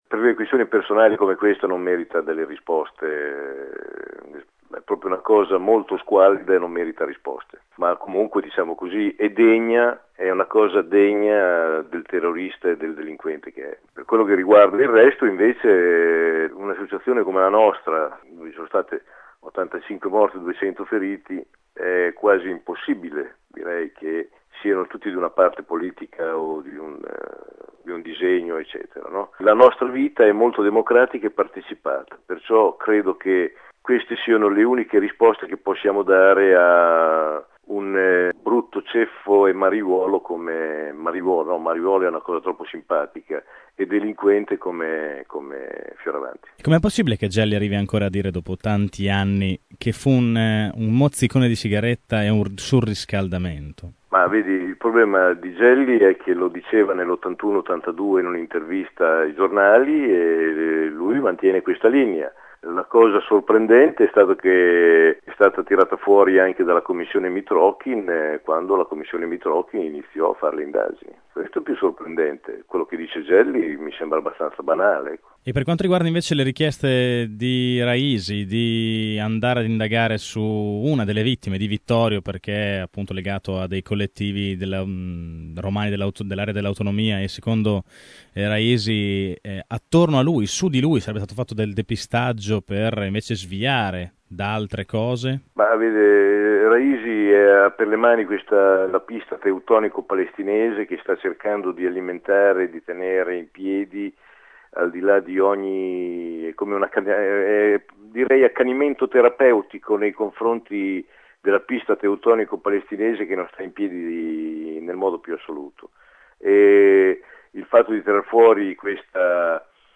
Bolognesi, questa mattina nei nostri studi, che detto che si aspetta delle risposte dal Governo.